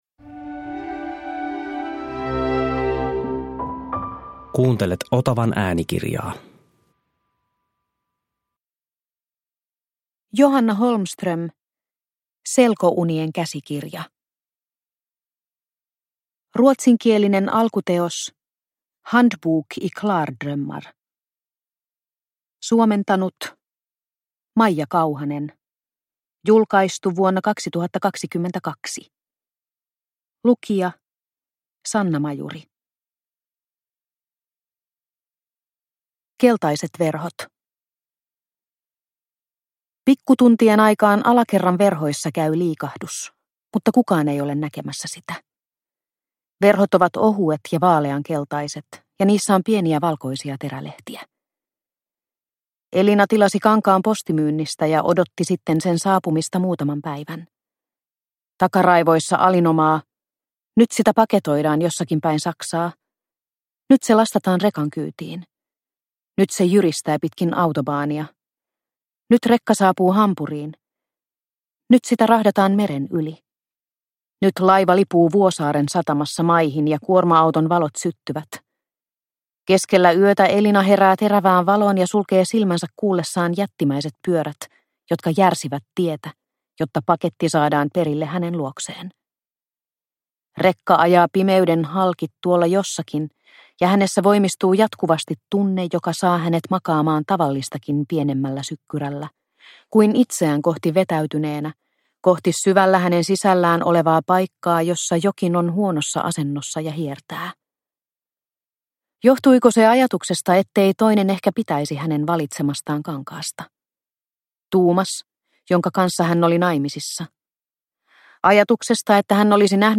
Selkounien käsikirja – Ljudbok – Laddas ner